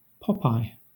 Ääntäminen
IPA : /ˈpɒpˌaɪ/